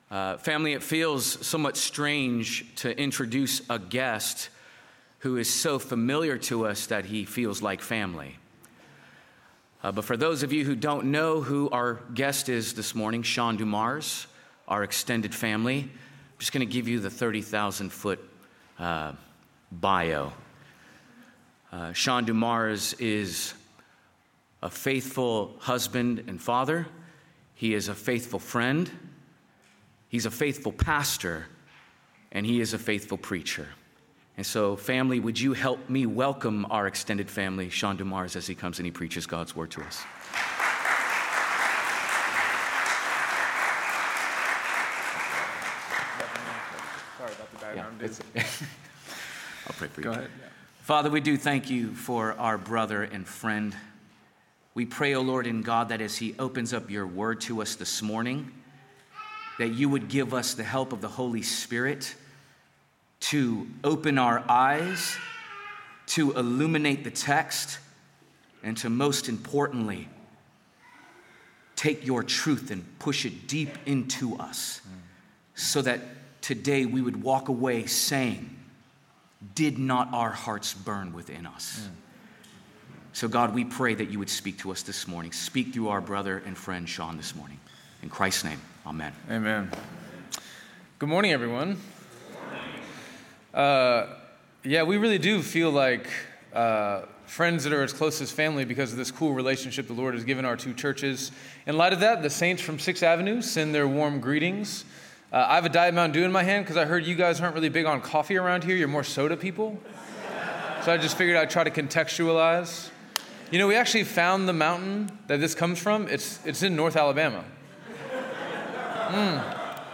Welcome to the sermon podcast of Trinity Church of Portland Oregon.